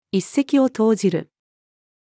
一石を投じる-female.mp3